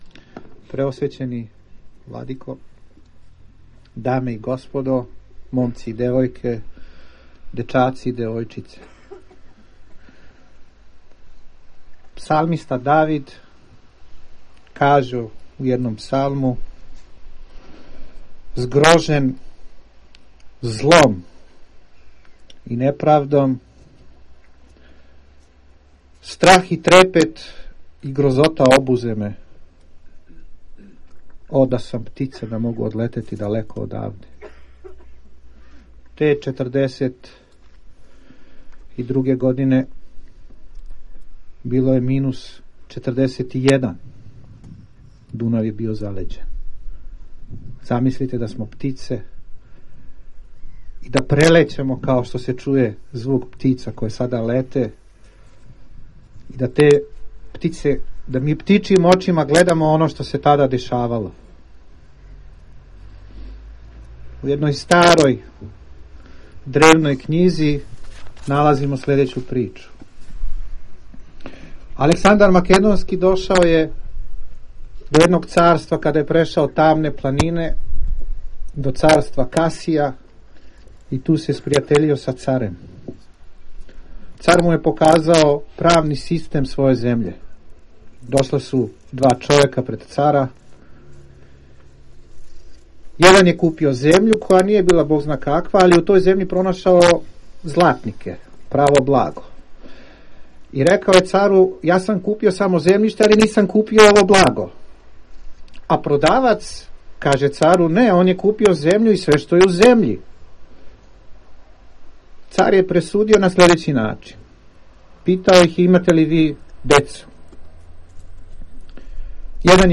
На Кеју жртава рације у Новом Саду, 23. јануара 2011. године, у организацији Православне Епархије бачке и Јеврејске општине Нови Сад, одржан је молитвени помен житељима нашег града који су невино пострадали од стране мађарских окупационих снага на данашњи дан пре 69 година.
• Беседа рабина Исака Асиела: